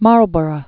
(märlbər-ə, -brə, môl-), First Duke of.